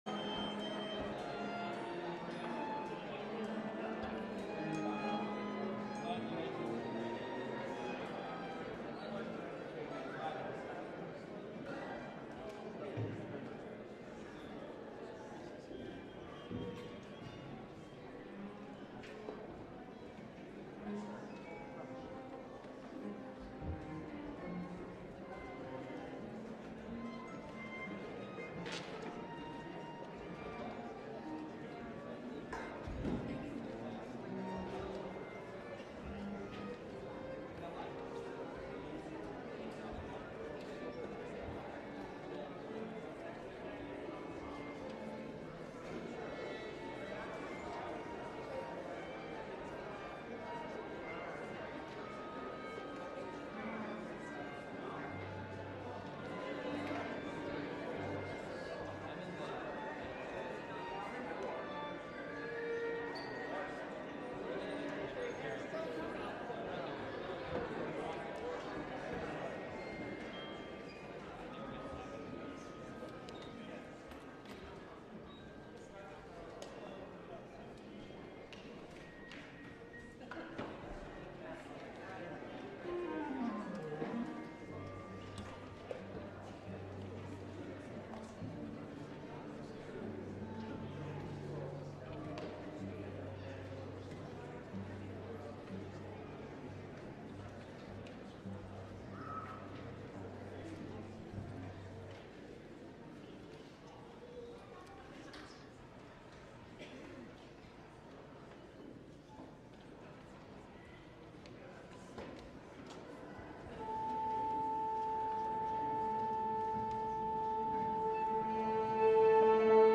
LIVE Evening Worship Service - Christmas Carol Sing